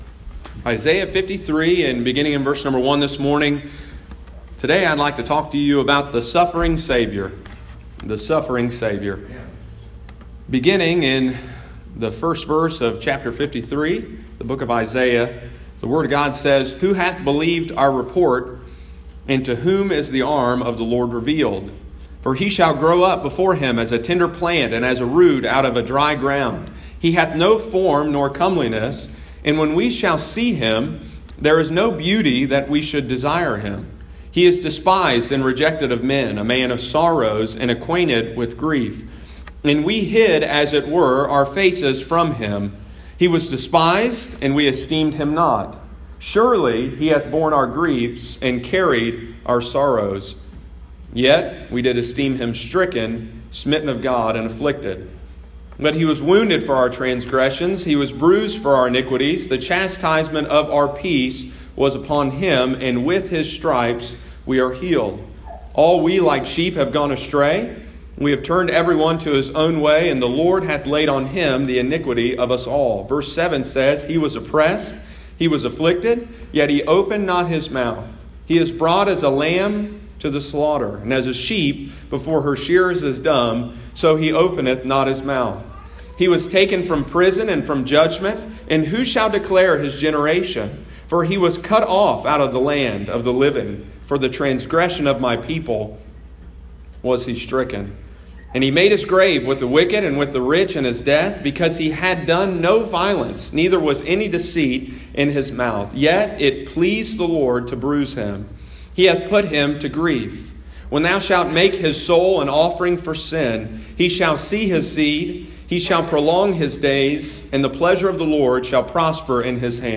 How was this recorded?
The Suffering Savior – Isaiah 53:1-12 – Lighthouse Baptist Church, Circleville Ohio